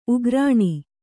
♪ ugrāṇi